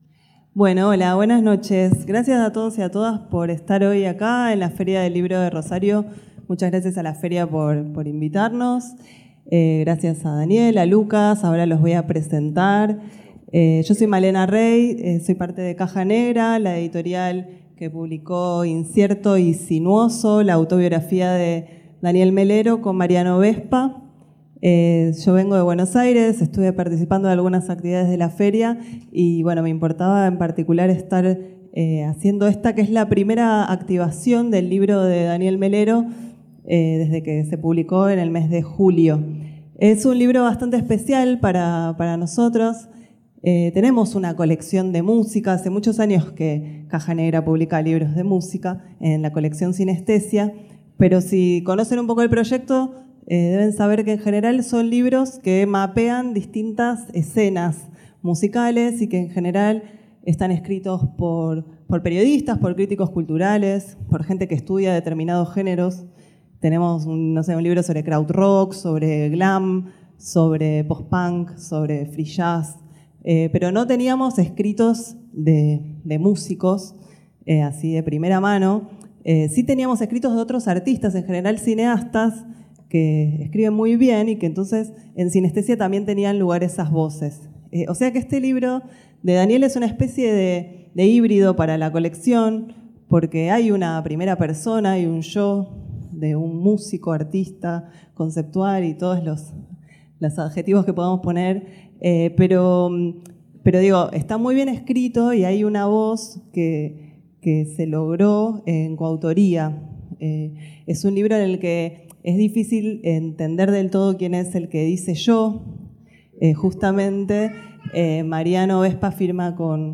Auditorio Angélica Gorodischer 21 hs. 07 de Septiembre 2024